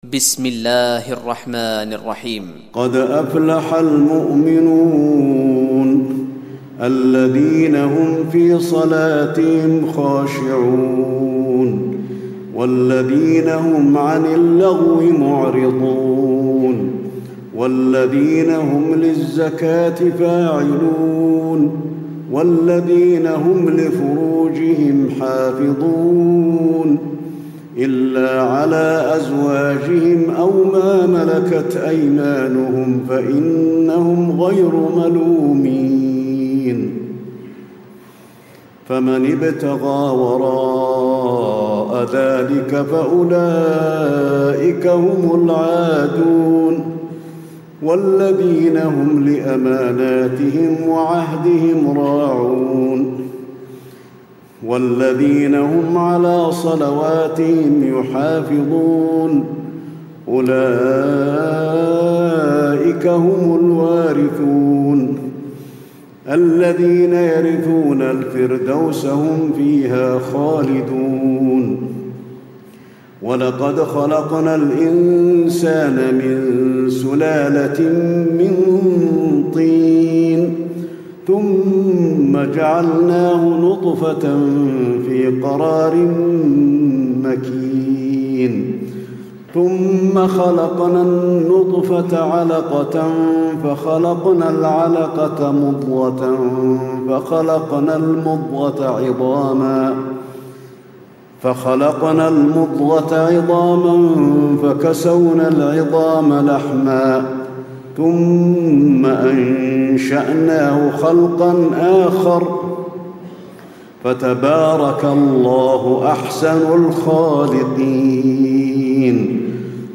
تراويح الليلة السابعة عشر رمضان 1436هـ سورتي المؤمنون و النور (1-20) Taraweeh 17 st night Ramadan 1436H from Surah Al-Muminoon and An-Noor > تراويح الحرم النبوي عام 1436 🕌 > التراويح - تلاوات الحرمين